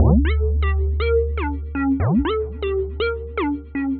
描述：我的歌曲《不要给我打电话》中的钟声
标签： 120 bpm Electronic Loops Synth Loops 689.24 KB wav Key : D
声道立体声